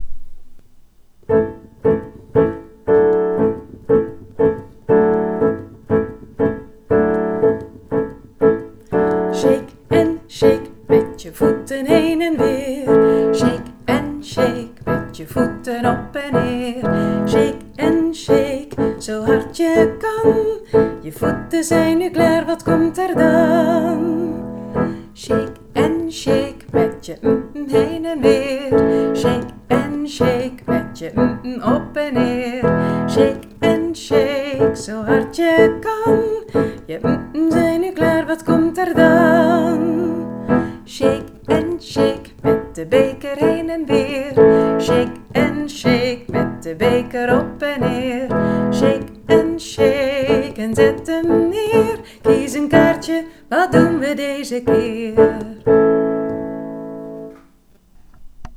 → Beluister de ingezongen versie van Het shake-lied
Shake_piano_met-zang.wav